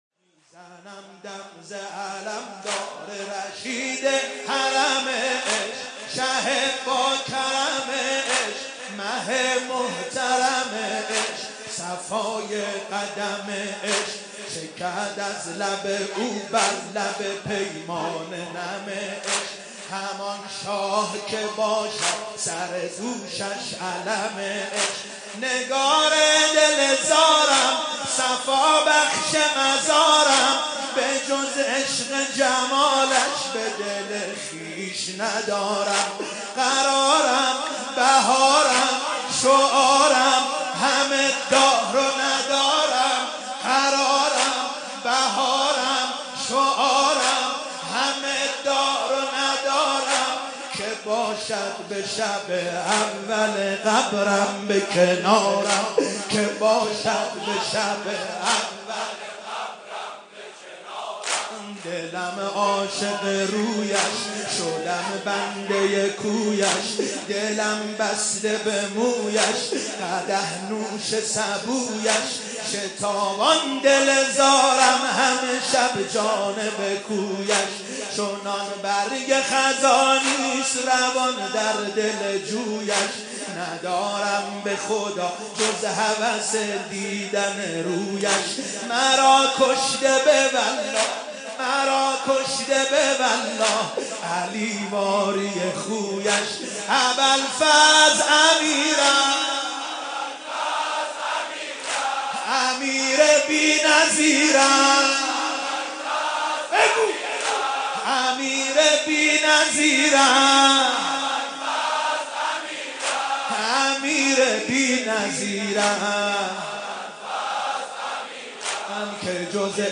بحر طویل